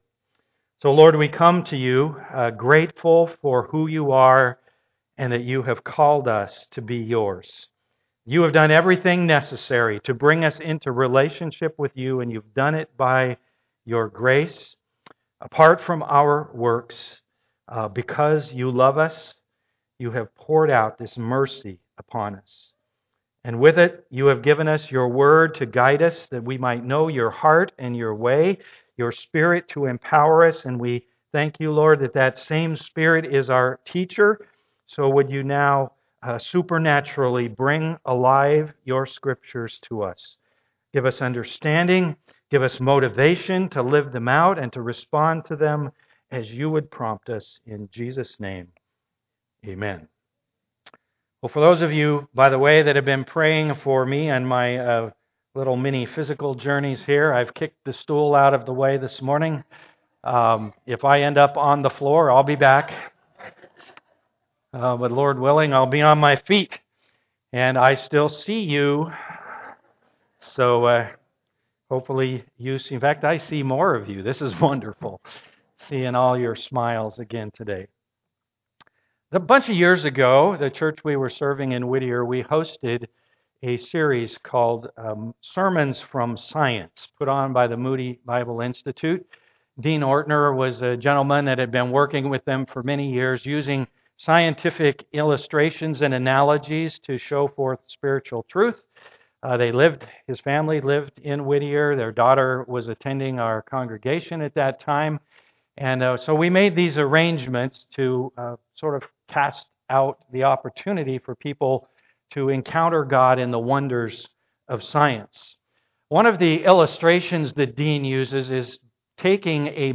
Church on the Move Passage: Acts 19:11-20 Service Type: am worship Thanks for joining our virtual service.